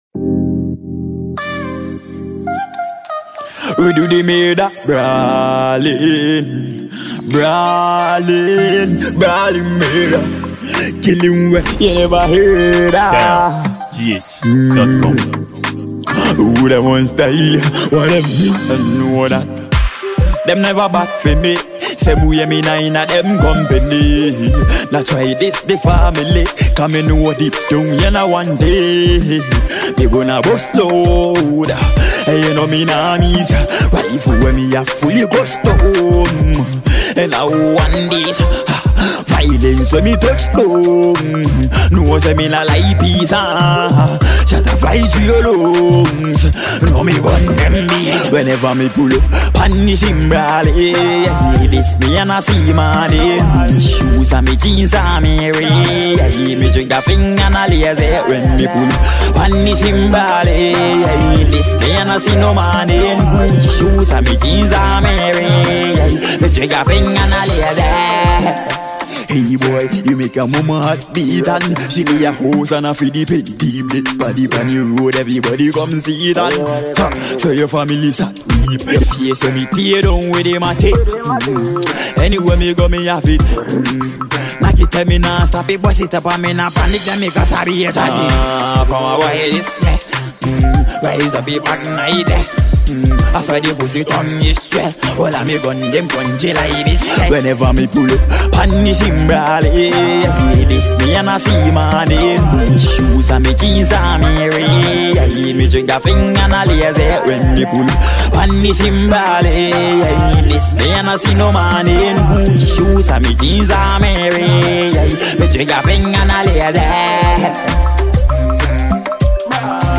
Ghanaian fast-rising dancehall musician